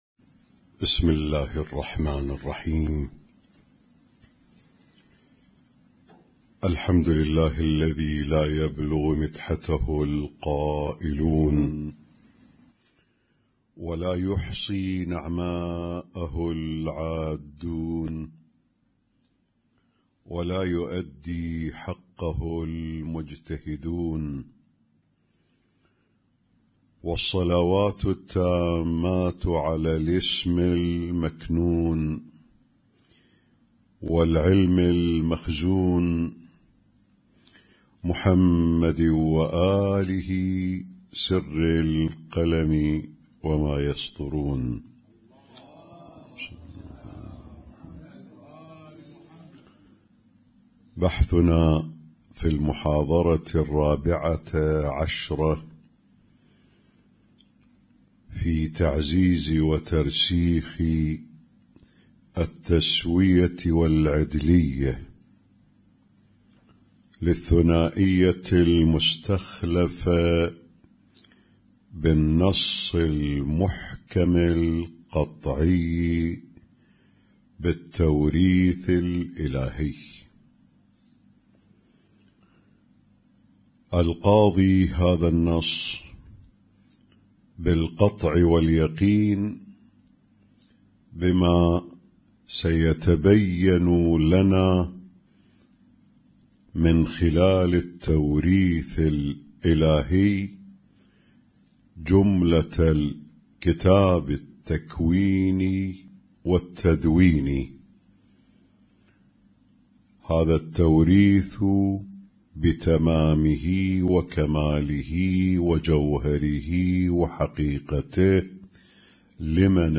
Husainyt Alnoor Rumaithiya Kuwait
اسم التصنيف: المـكتبة الصــوتيه >> الدروس الصوتية >> الرؤية المعرفية الهادفة